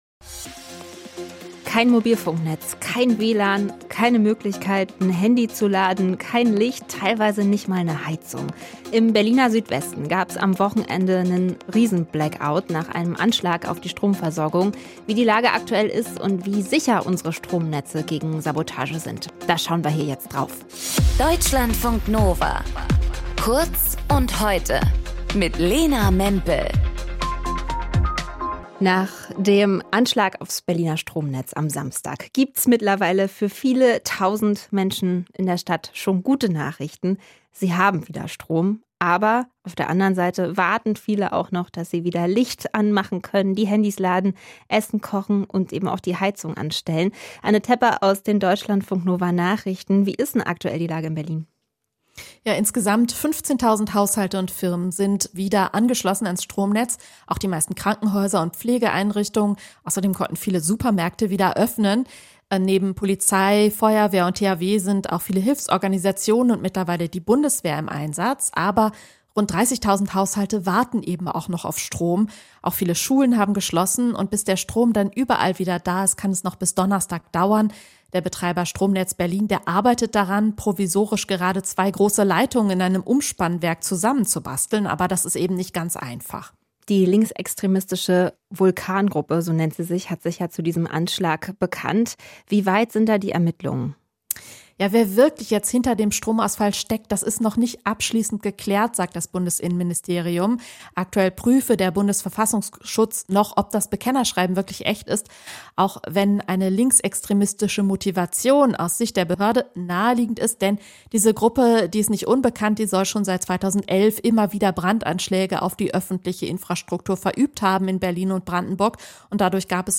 Moderation:
Gesprächspartnerin: